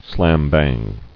[slam-bang]